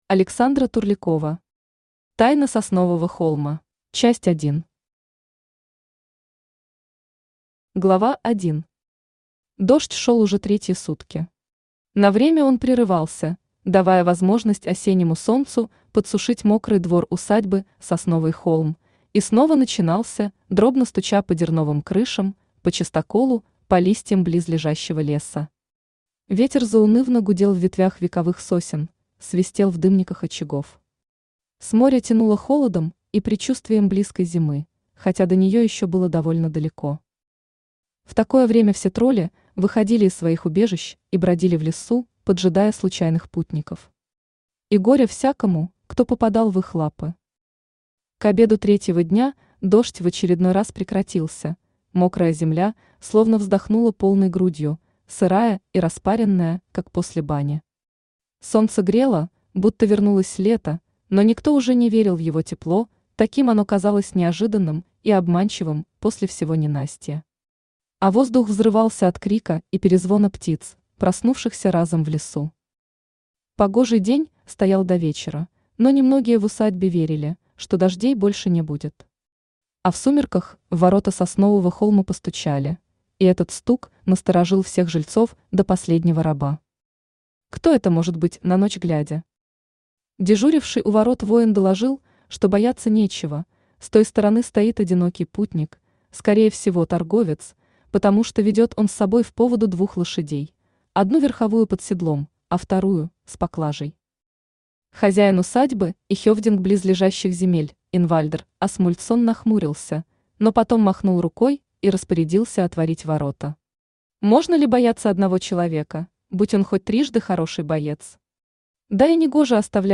Аудиокнига Тайна Соснового холма | Библиотека аудиокниг
Aудиокнига Тайна Соснового холма Автор Александра Турлякова Читает аудиокнигу Авточтец ЛитРес.